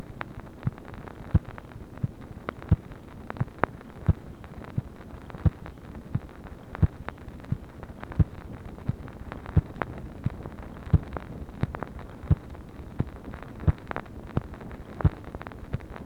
MACHINE NOISE, February 8, 1964
Secret White House Tapes | Lyndon B. Johnson Presidency